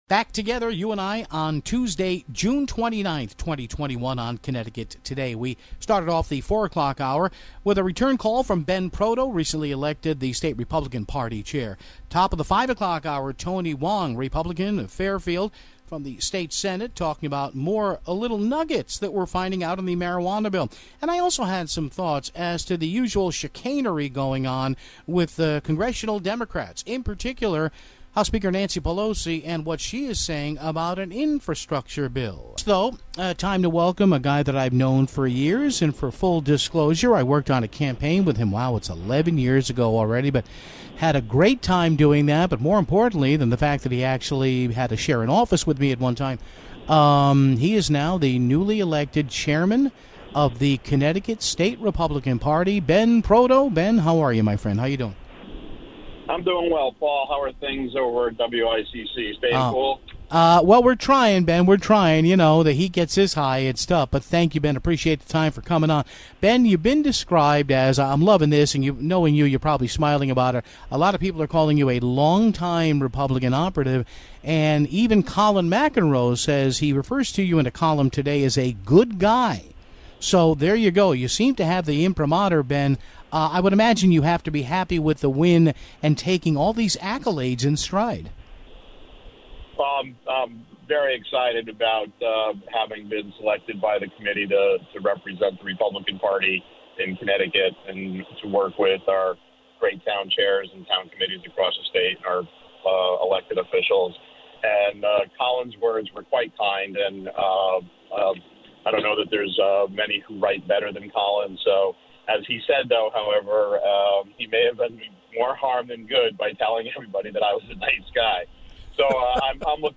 Finally, State Senator Tony Hwang called in to talk about the recreational Marijuana bill which takes effect starting Thursday (18:39).